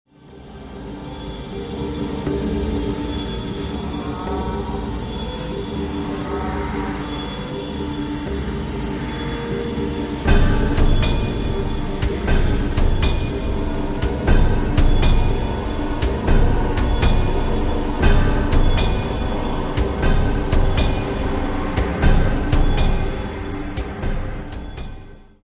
a dream-like soundtrack